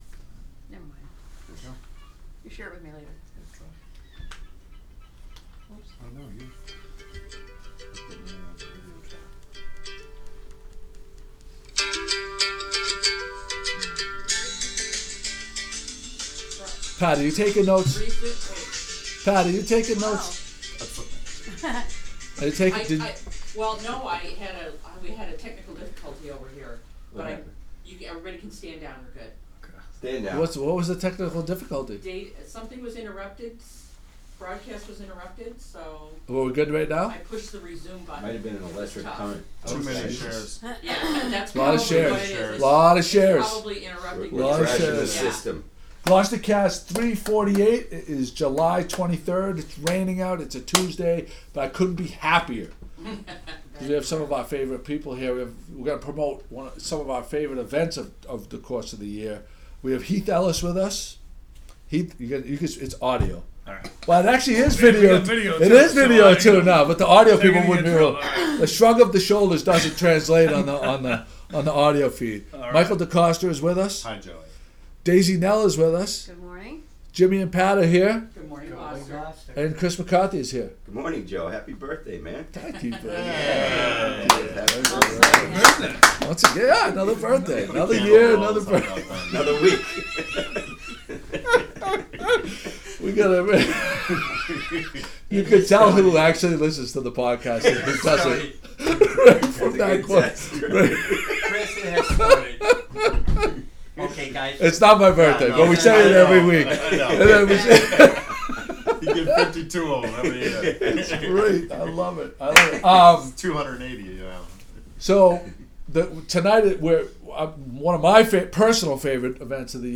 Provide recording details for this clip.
Taped At the Crow’s Nest